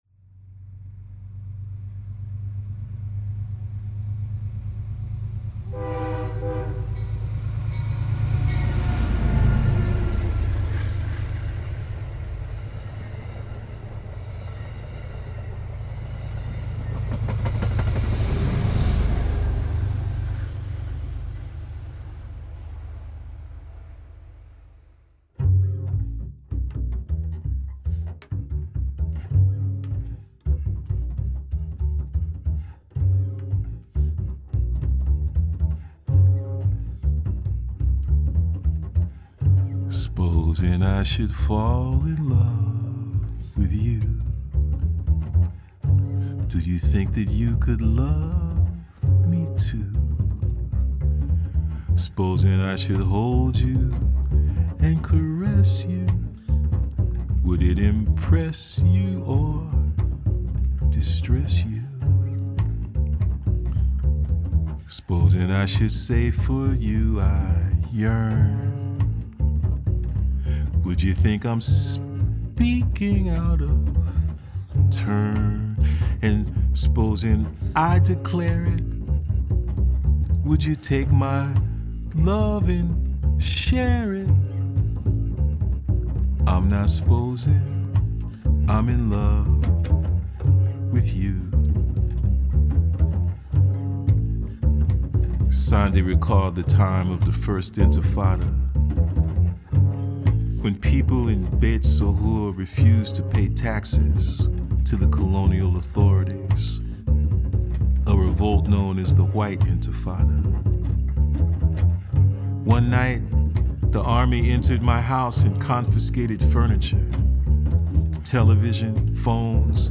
bassist